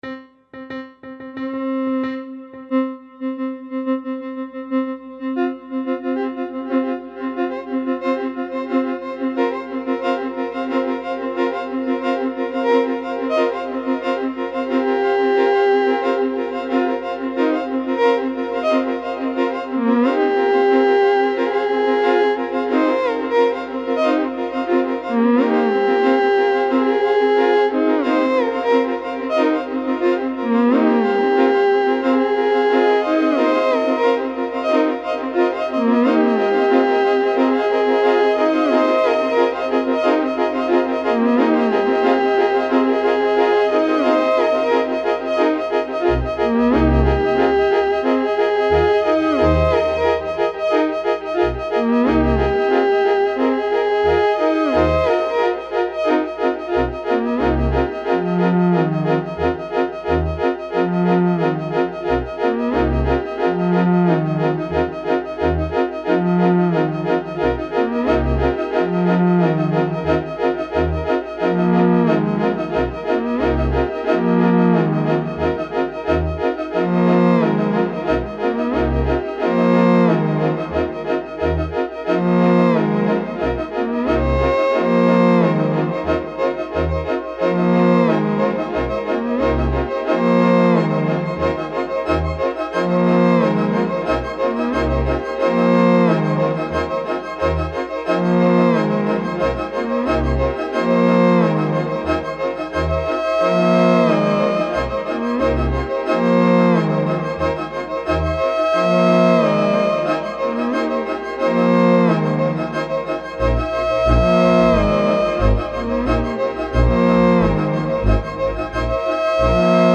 Three-Legged Race (stereo mix -listen)
A technique called "Live Looping" allows the musician to play phrases and, in turn, the looping hardware/software (in this case a laptop computer) plays back the phrases in a continuous, looping fashion. While the loops are playing back, the musician can add (overdub) additional material.  Thus, an entire ensemble texture can be built up from a one-note-at-a-time instrument, such as the Theremin.
Three-Legged Race uses four sets of synchronized loops under computer control.  The various loops are placed throughout the surround-sound field.